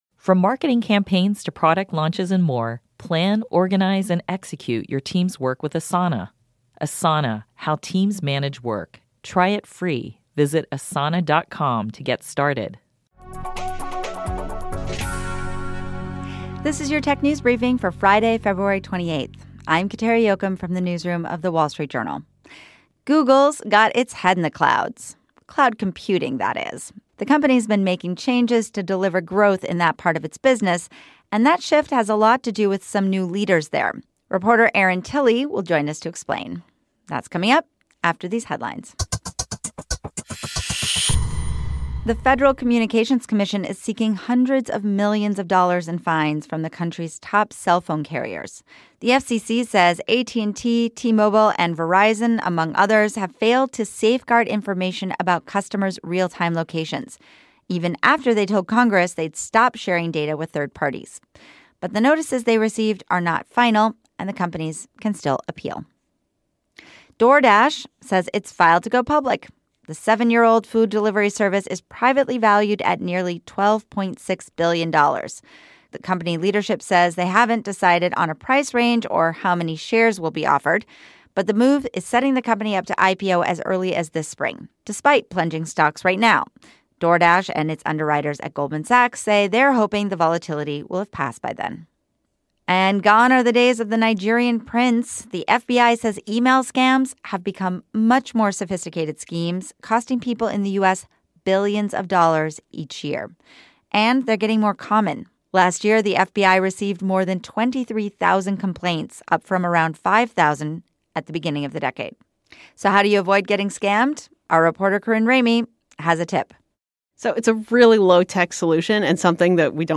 Google has been making changes to try and deliver growth in its cloud computing business - where it currently has only four percent of the total market. Reporter